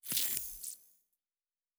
Sci-Fi Sounds / Electric / Device 5 Stop.wav
Device 5 Stop.wav